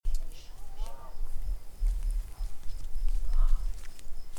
Class A EVPs that have been captured by our team.
The screams came from all over and were heard by everyone…